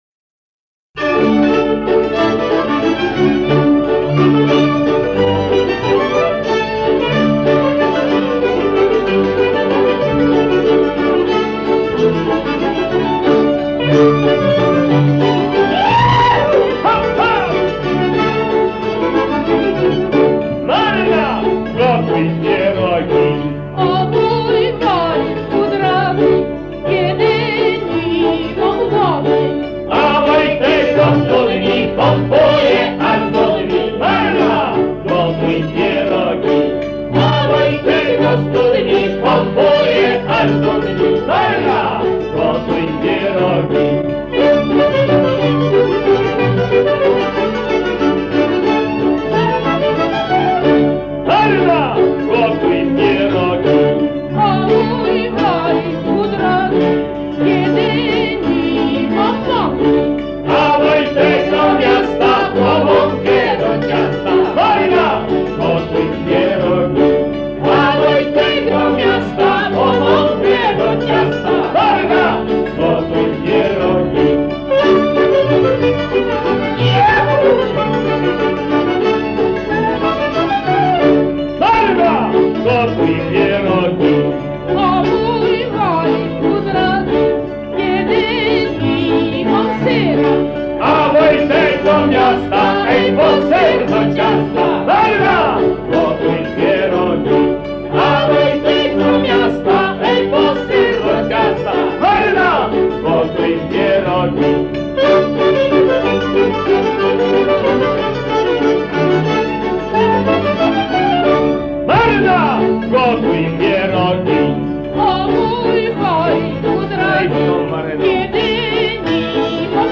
Ponizej pliki dźwękowe mp3 z e zbiorów Muzeum Etnograficznego w Rzeszowie.